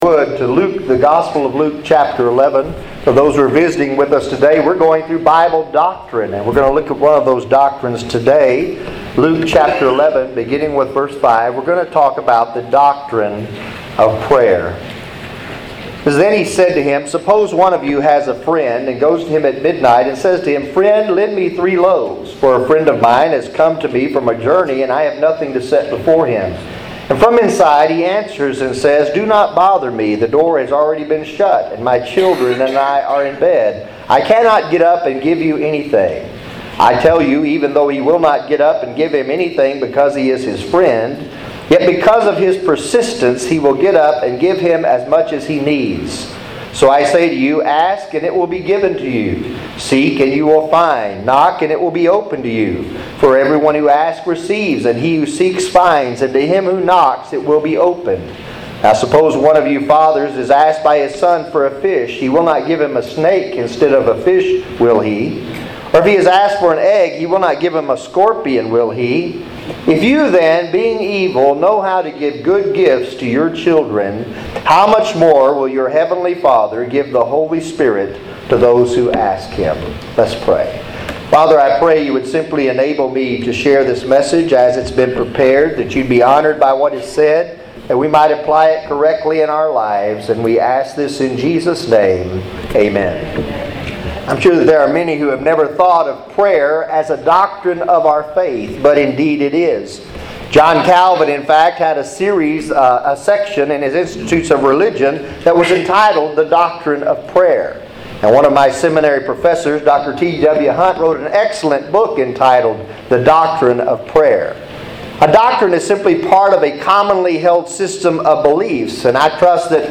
Sermons | Providential Baptist Church